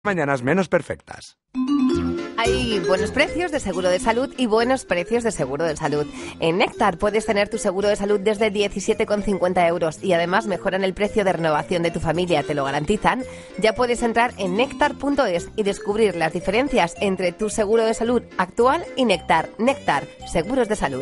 Para dar mayor notoriedad a la campaña, además de las cuñas grabadas, se realizaron menciones en directo con los conductores de los principales programas de la mañana: